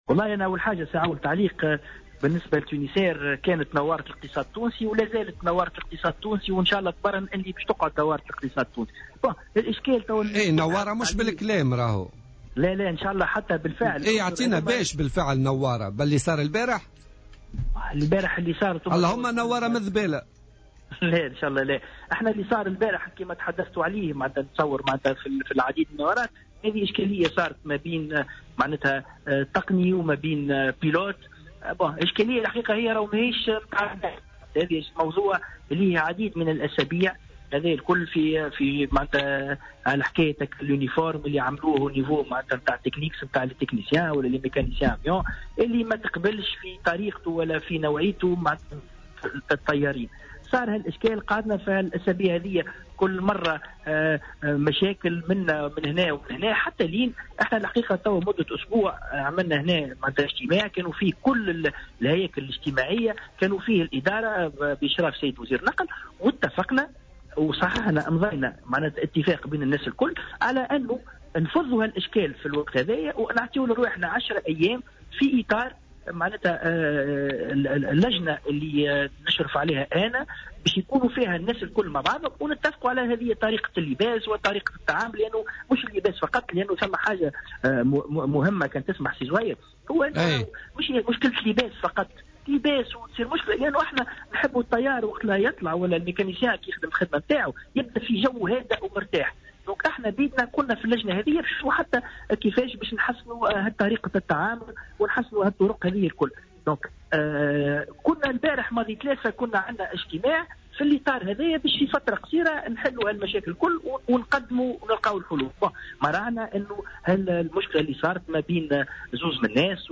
وأضاف بن أحمد خلال مداخلة له اليوم في برنامج "بوليتيكا" أنه تم فتح تحقيق إداري على خلفية حادثة مطار تونس قرطاج لتحميل المسؤوليات، إضافة إلى اتخاذ جملة من الإجراءات الأخرى على غرار التخلي عن اصطحاب التقنيين في الرحلات الجوية إلى الوجهات التي لا يوجد فيها تعاون تقني، بالإضافة إلى تعليق ارتداء الزي الموحد لحلّ الأزمة.